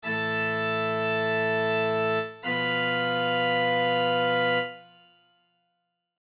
quinta-y-tritono.mp3